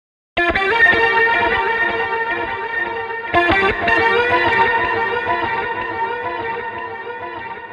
描述：Electric guitar, clean, phaser/flange type of sound, sorta blues yet spacey
标签： blues clean guitar flange space electric phaser delay
声道立体声